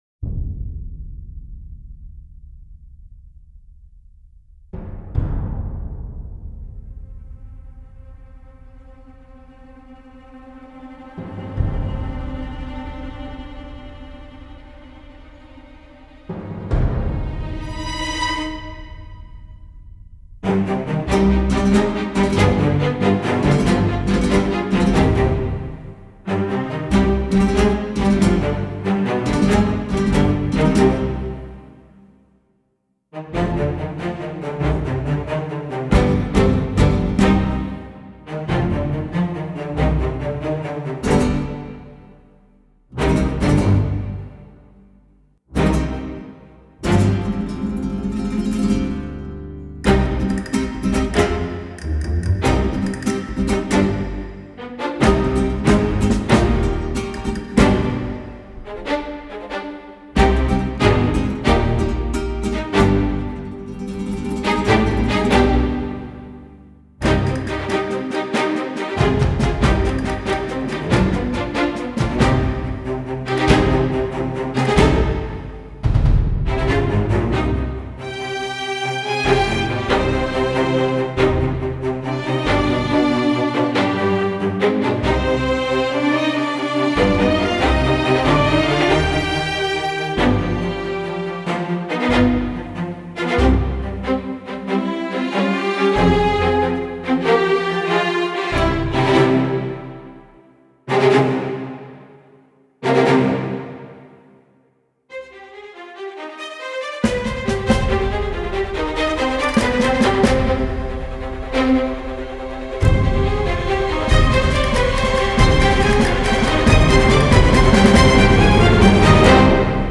Le mixage est léger, limpide. Rafraichissant.
guitare un brin flamenco, trompette, castagnettes…